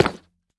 Minecraft Version Minecraft Version snapshot Latest Release | Latest Snapshot snapshot / assets / minecraft / sounds / block / nether_bricks / break5.ogg Compare With Compare With Latest Release | Latest Snapshot
break5.ogg